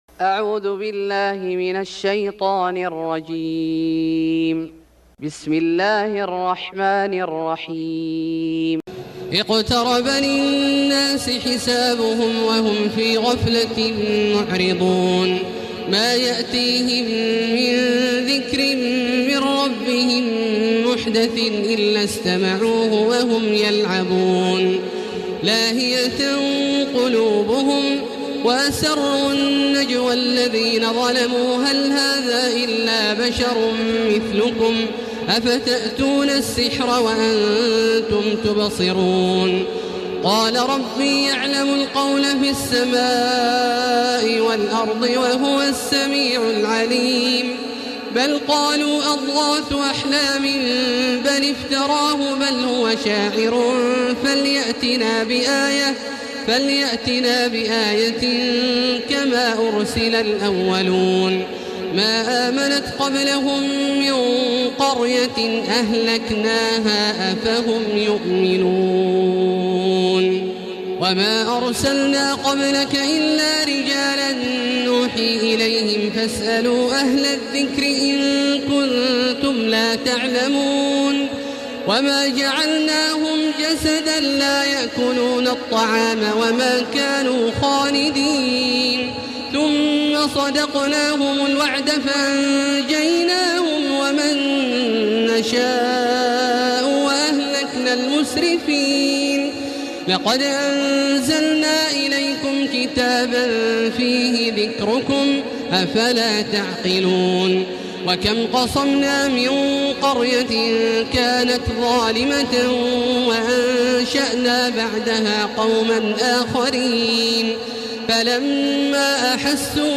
سورة الأنبياء Surat Al-Anbiya > مصحف الشيخ عبدالله الجهني من الحرم المكي > المصحف - تلاوات الحرمين